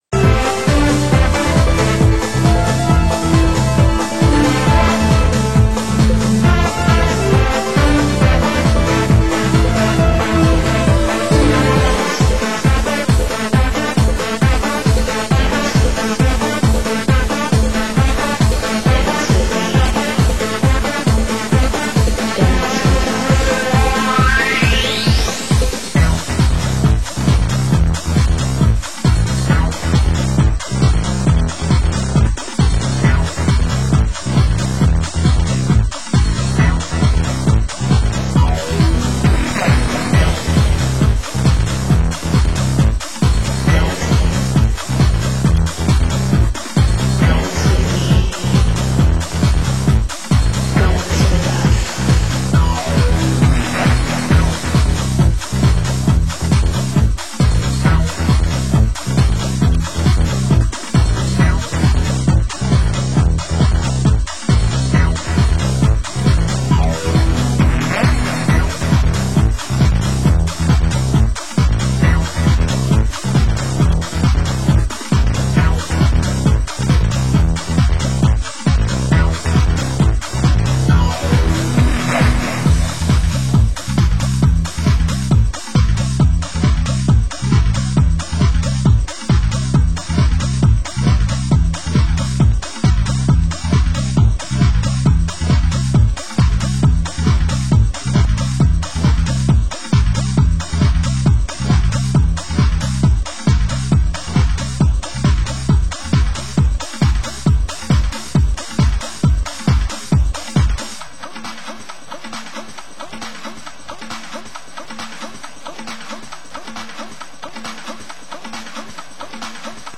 Genre: Progressive
Genre: UK House